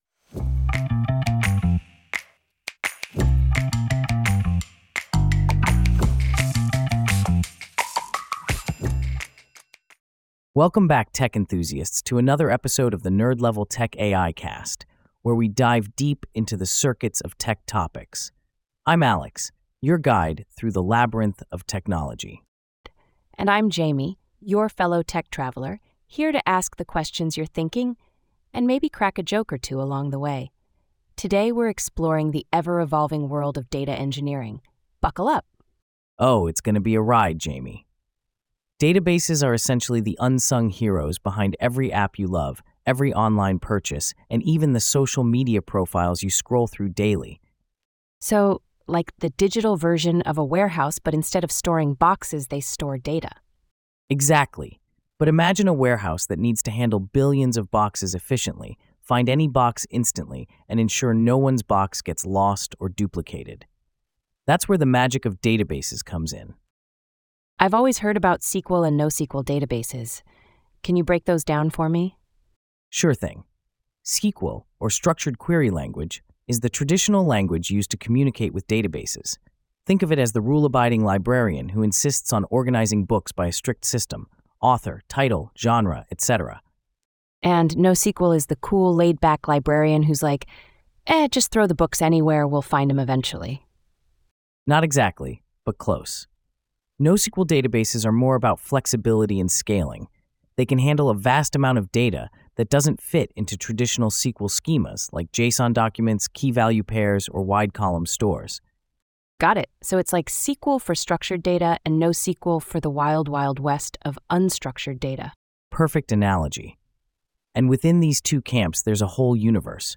Listen to the AI-generated discussion